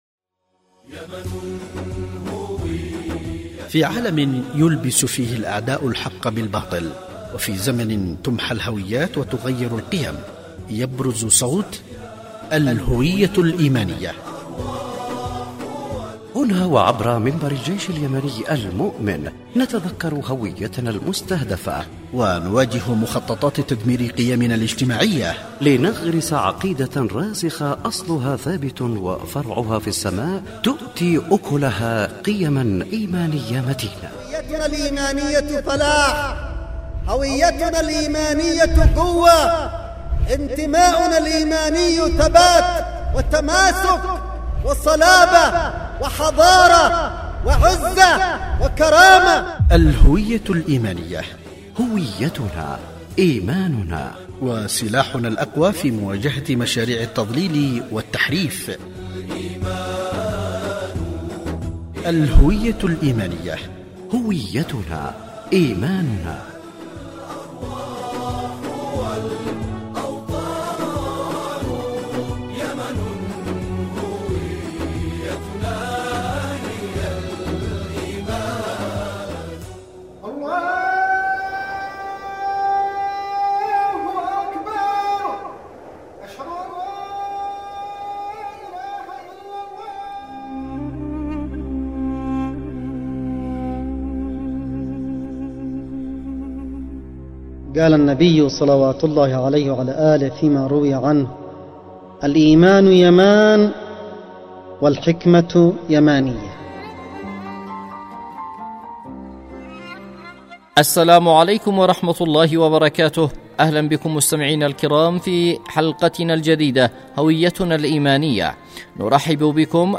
الهوية الإيمانية، برنامج إذاعي يتحدث عن الهوية الايمانية واهم الاعمال والبرامج التي يعمل عليها لطمس وتغيير الهوية وكيف نواجه مخططات الأعداء